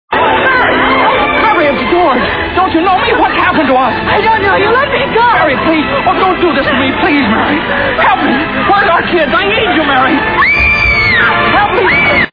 Donna Reed screams.